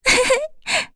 Frey-Vox_Happy1.wav